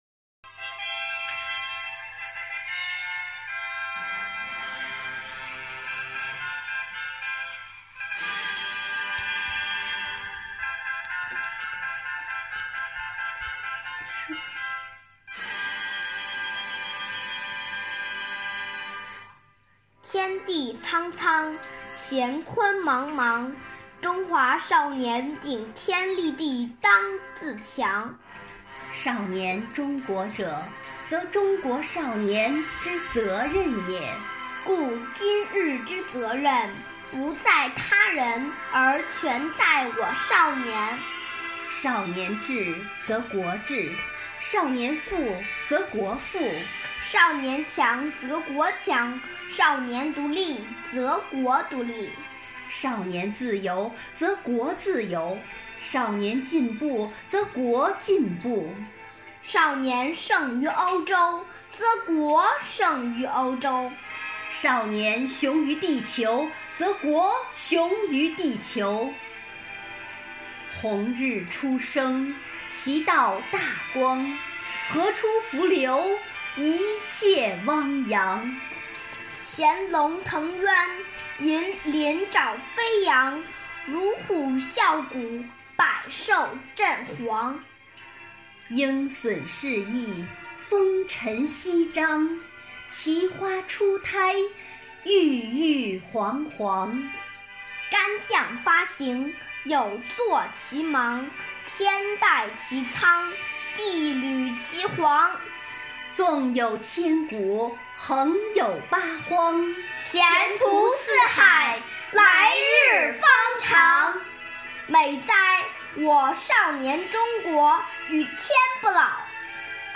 生活好课堂幸福志愿者中国钢研朗读服务（支）队第十一次云朗诵会在五月开启，声声朗诵、篇篇诗稿赞颂红五月，讴歌美好生活，吟诵美丽中国。
《少年中国说》朗诵